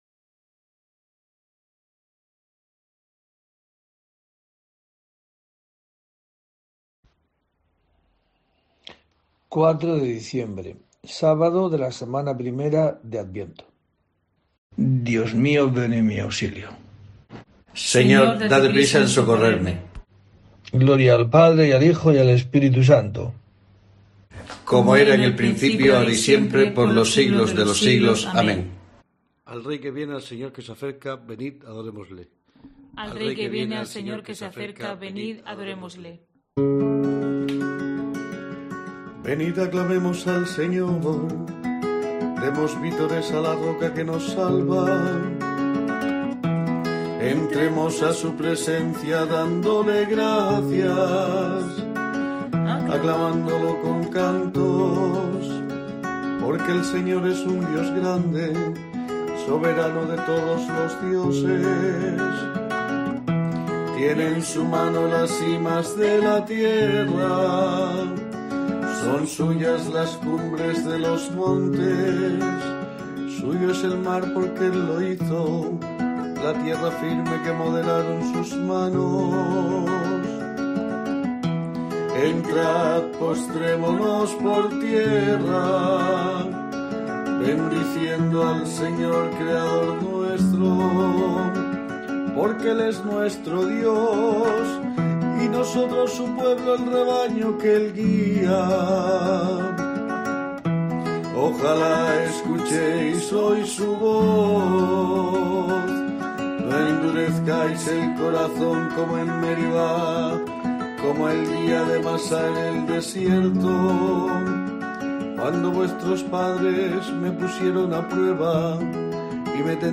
04 de diciembre: COPE te trae el rezo diario de los Laudes para acompañarte